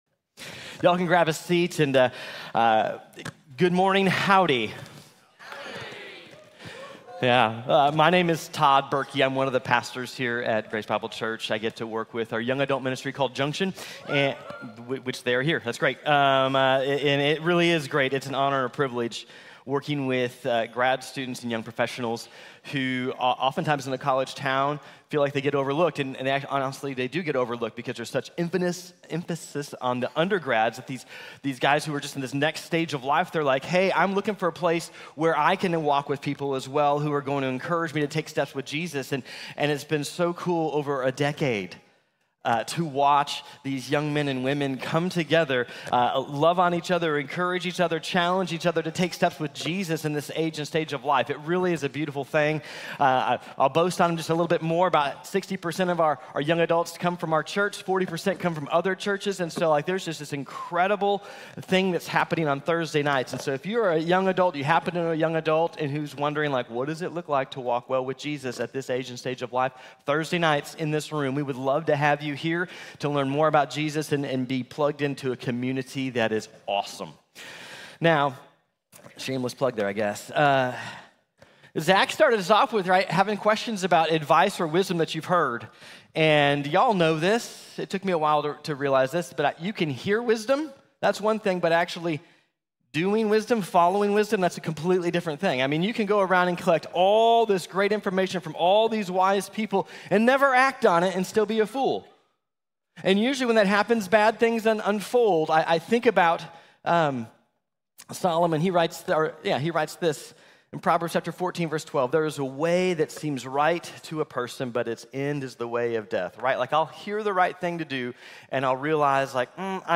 Going Our Own Way | Sermon | Grace Bible Church